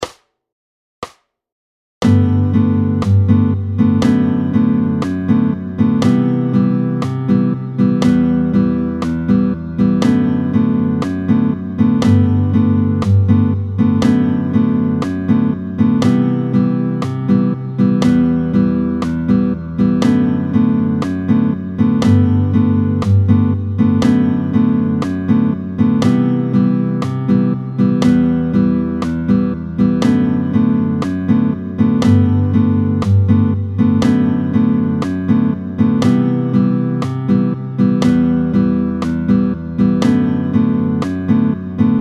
07-04 La mesure à 2/2. Vite tempo 120